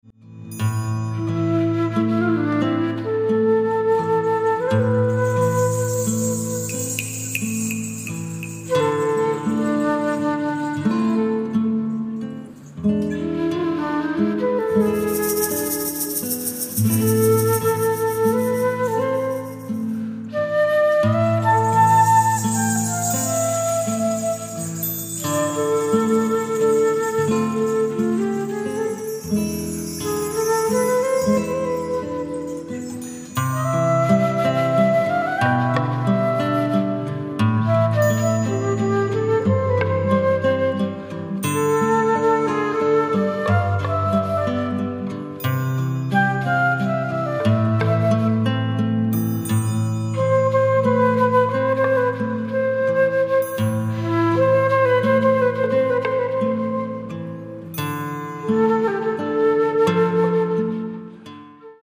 at studio Voice
ビン笛、フルート、ギター